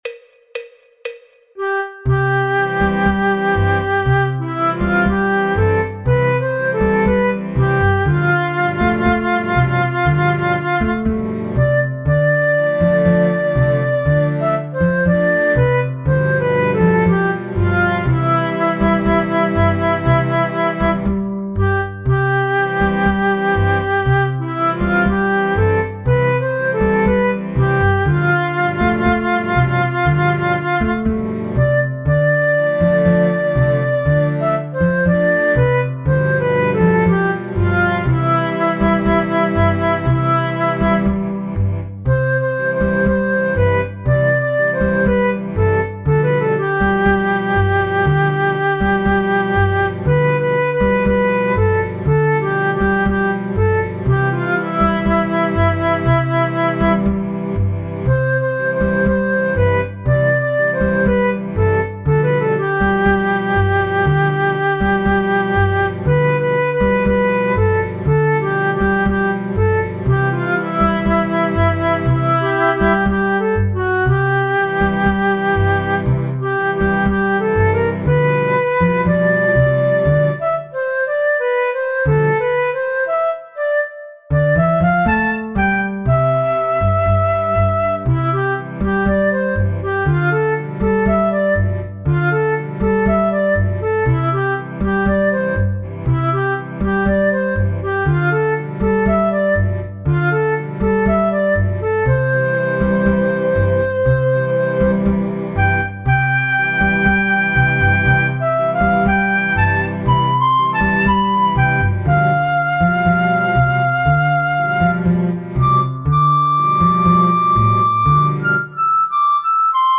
El MIDI tiene la base instrumental de acompañamiento.
Flauta Dulce, Flauta Travesera
Habanera, Música clásica